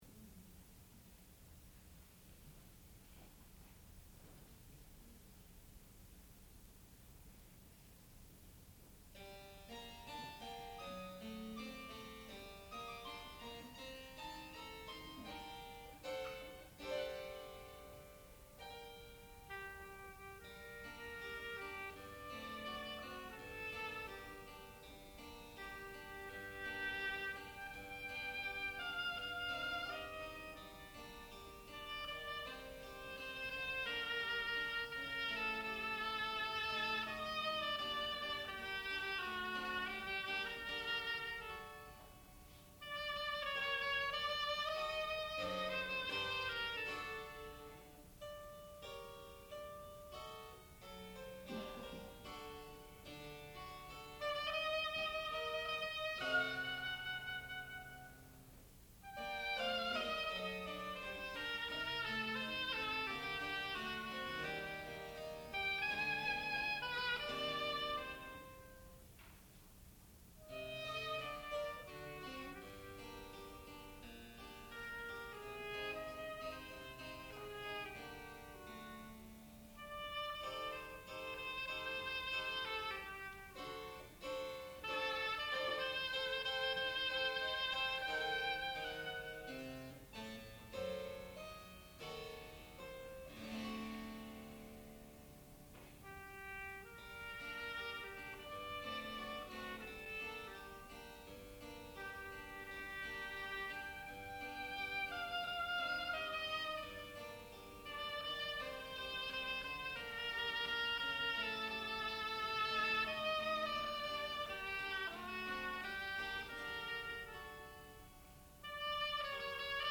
sound recording-musical
classical music